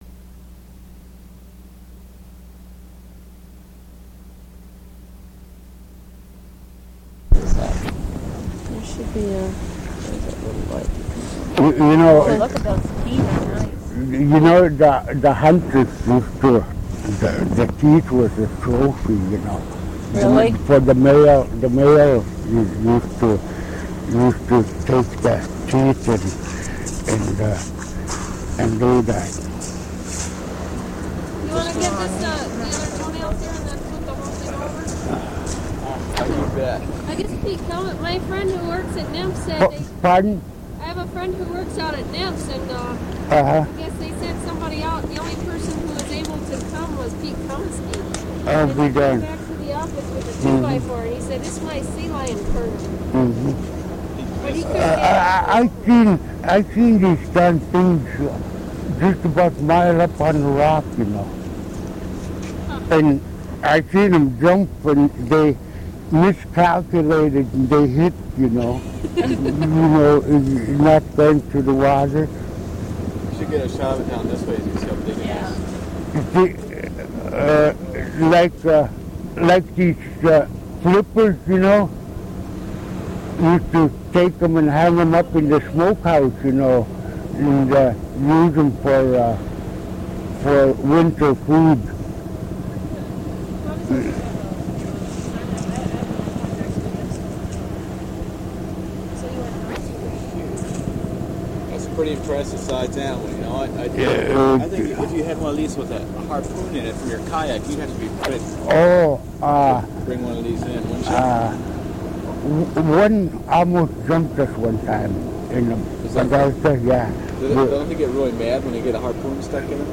Topics include kayaks, beach talk, surf, and sea lion stories. Quality: poor.